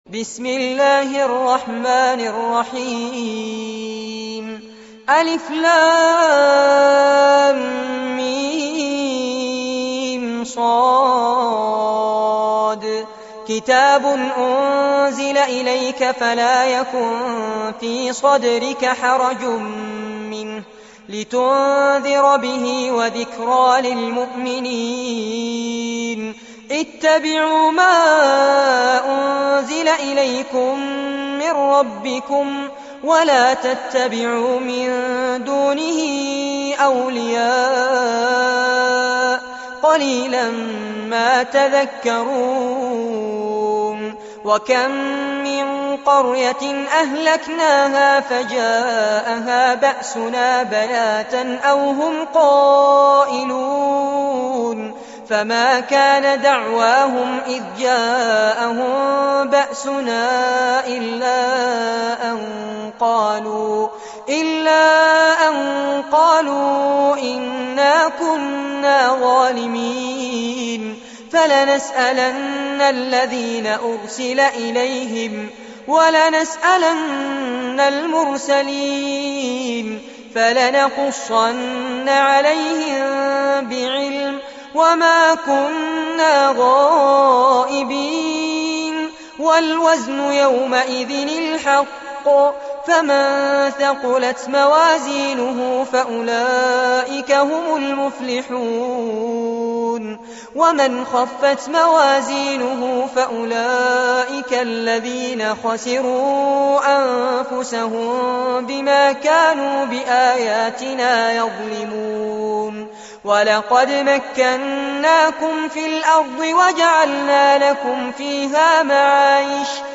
سورة الأعراف- المصحف المرتل كاملاً لفضيلة الشيخ فارس عباد جودة عالية - قسم أغســـــل قلــــبك 2